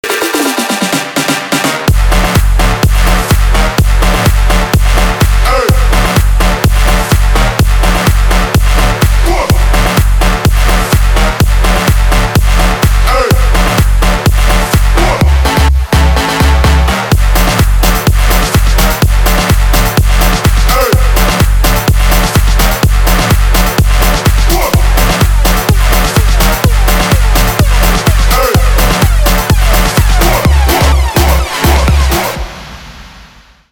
громкие
мощные
Electronic
Bass
electro house
бодрые